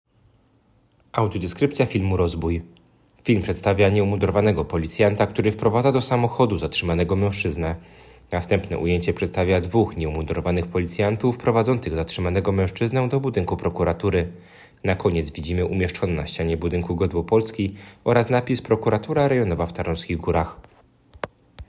Nagranie audio audiodeskrypcja_filmu_rozboj.m4a
Opis nagrania: Audiodeskrypcja filmu rozbój